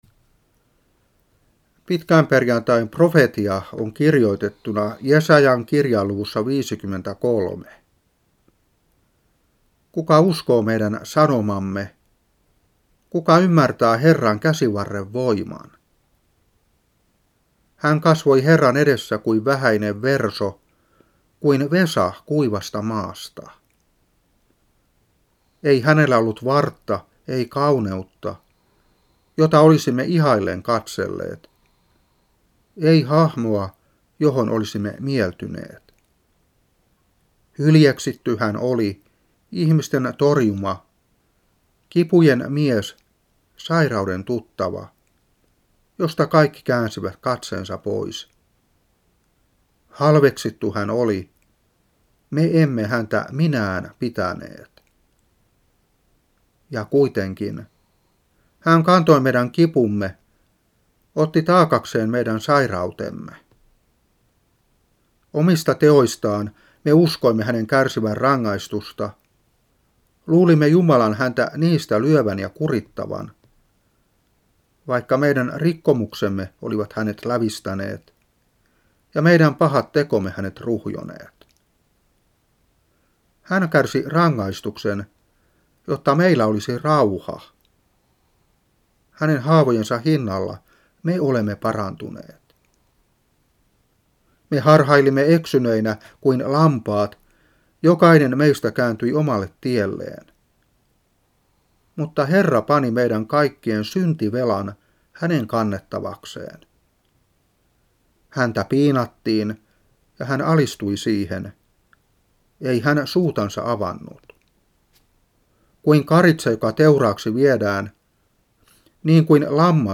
Saarna 2016-3.